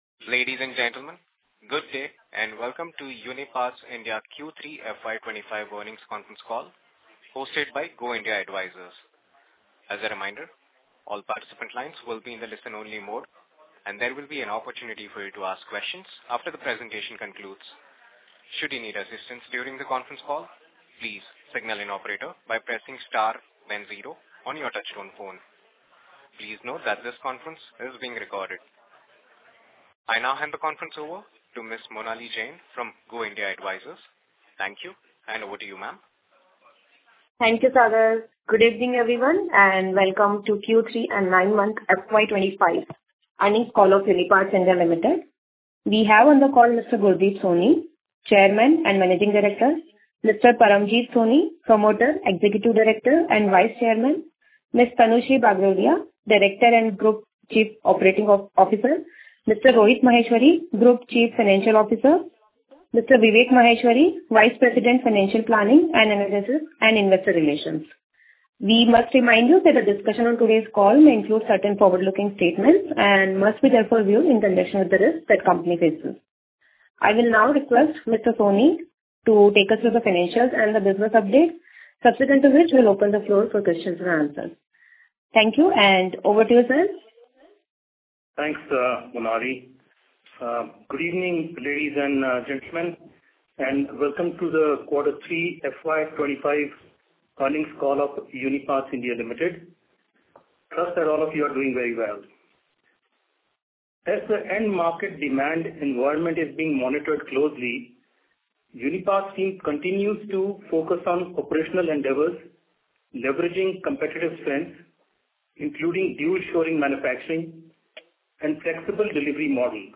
Q3EarningsCallRecording2025.mp3